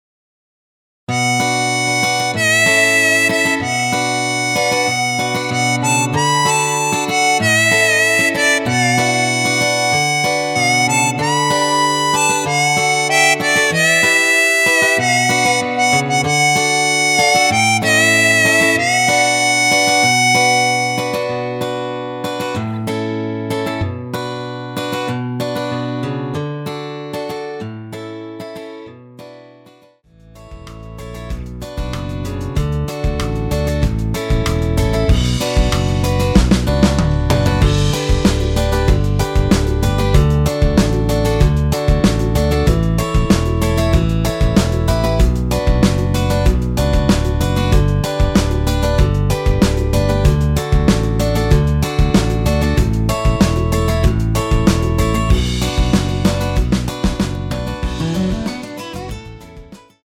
원키에서(+7)올린 MR입니다.
Bm
앞부분30초, 뒷부분30초씩 편집해서 올려 드리고 있습니다.